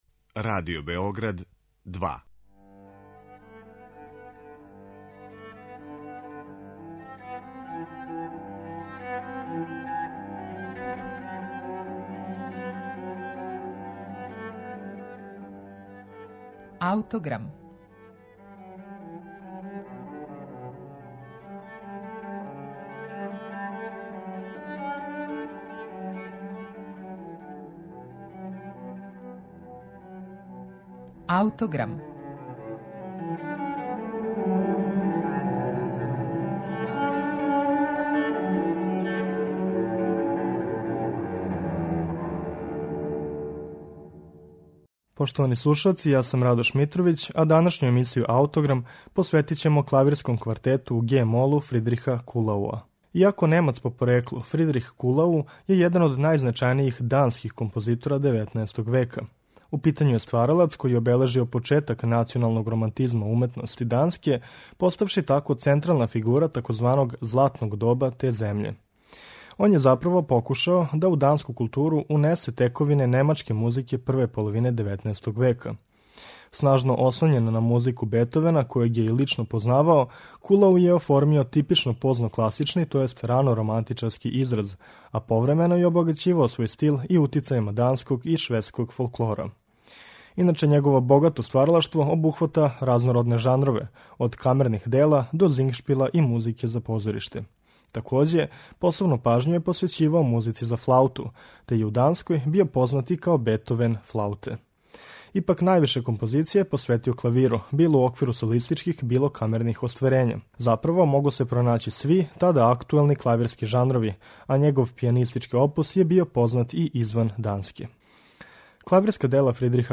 Снажно ослоњен на музику Бетовена, којег је и лично познавао, Кулау је оформио типичан рано романтичарски израз, а повремено је свој стил обогаћивао и утицајима данског и шведског фолклора.
У том контексту се могу посматрати и његови клавирски квартети.